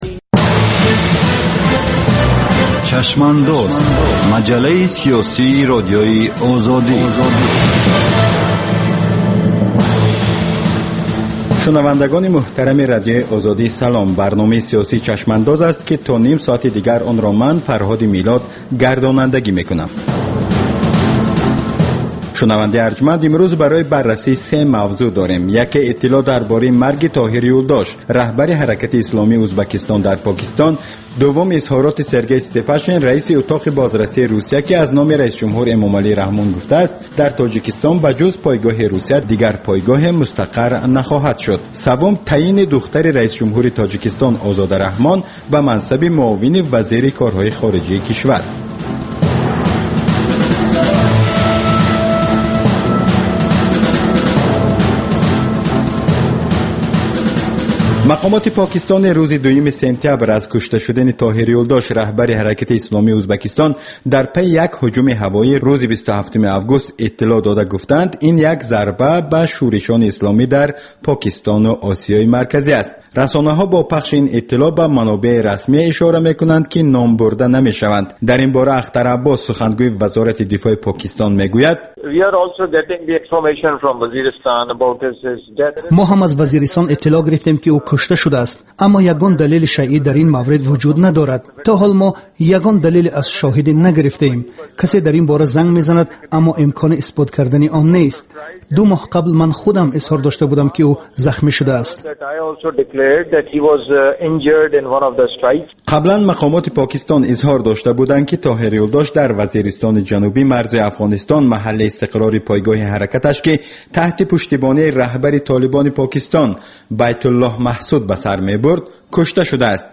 Гуфтугӯ бо коршиносон, масъулини давлатӣ, намояндагони созмонҳои байналмилалӣ.